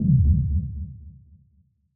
Impact 26.wav